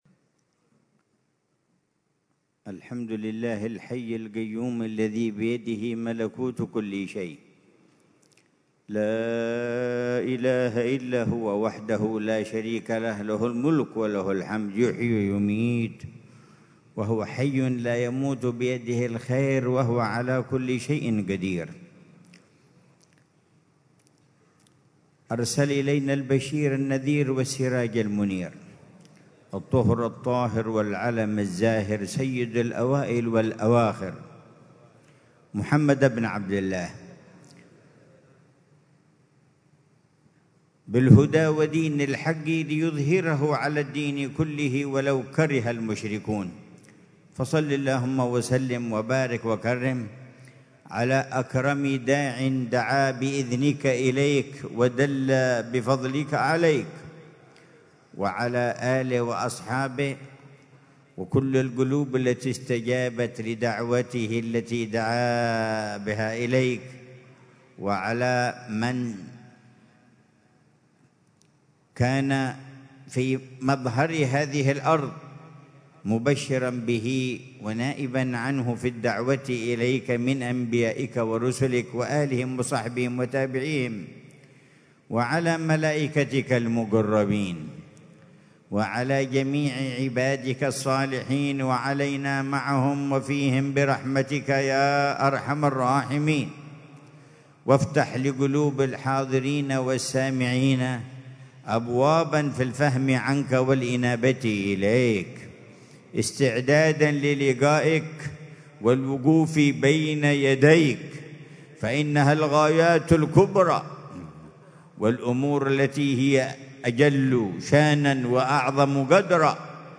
محاضرة العلامة الحبيب عمر بن محمد بن حفيظ ضمن سلسلة إرشادات السلوك، ليلة الجمعة 23 محرم 1447هـ في دار المصطفى بتريم، بعنوان: